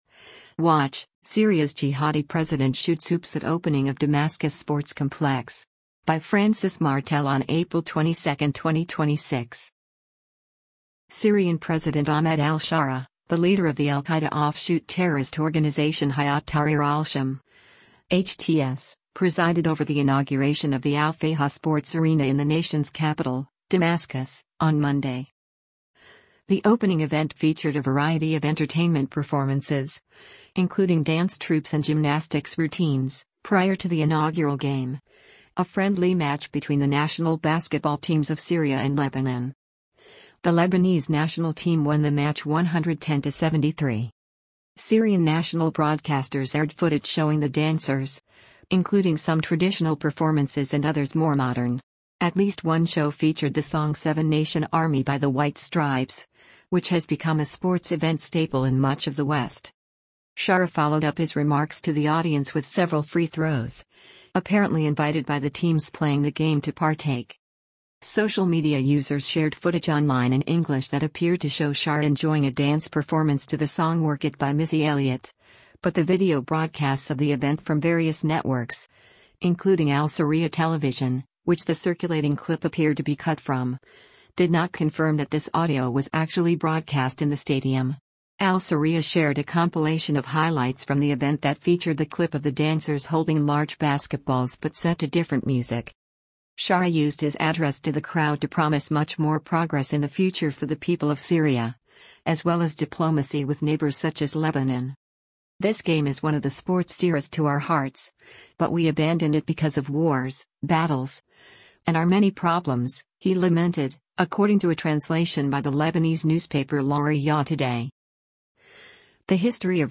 Watch: Syria’s Jihadi President Shoots Hoops at Opening of Damascus Sports Complex
The opening event featured a variety of entertainment performances, including dance troupes and gymnastics routines, prior to the inaugural game, a friendly match between the national basketball teams of Syria and Lebanon.
Sharaa followed up his remarks to the audience with several free throws, apparently invited by the teams playing the game to partake.